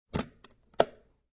Descarga de Sonidos mp3 Gratis: maletin abierto.